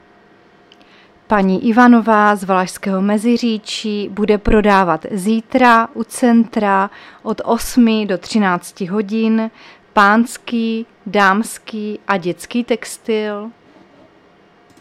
Záznam hlášení místního rozhlasu 26.6.2024
Zařazení: Rozhlas